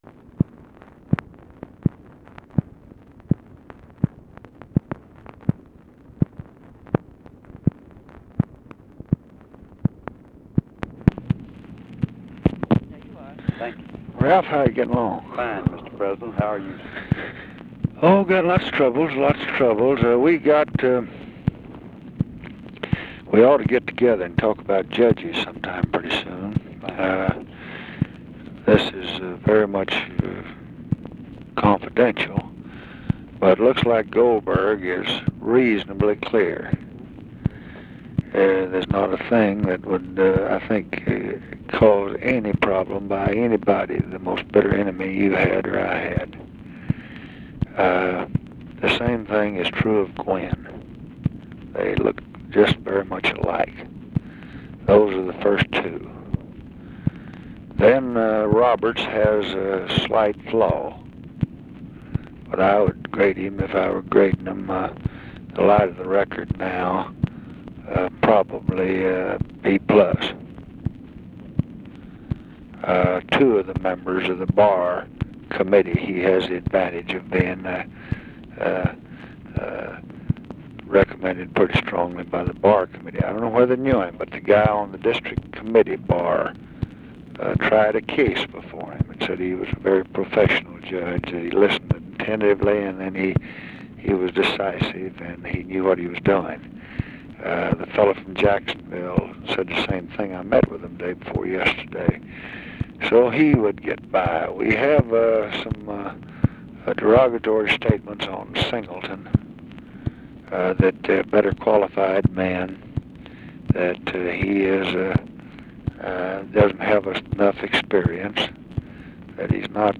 Conversation with RALPH YARBOROUGH, May 20, 1966
Secret White House Tapes